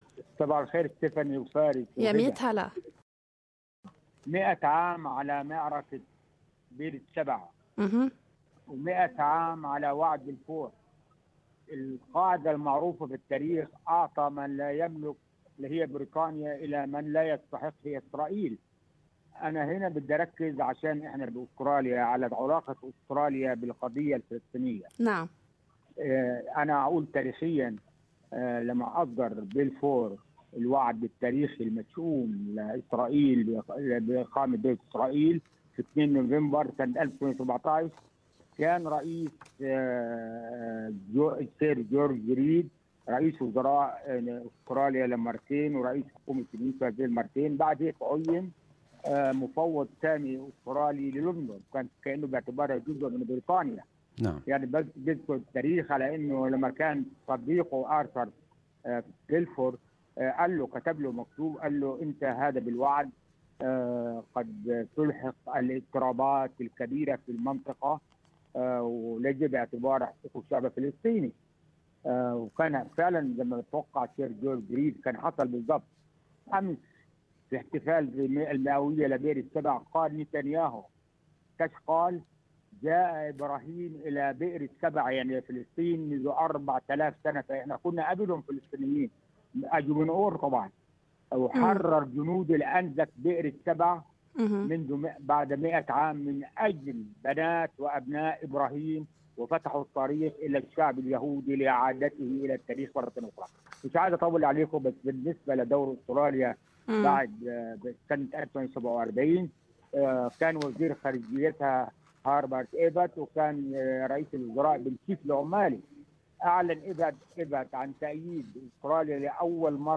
Talkback: Balfour Declaration 100th anniversary
Our listeners participated this morning in GMA talkback about the 100th anniversay of Balfour declaration.